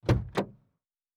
Car Door (4).wav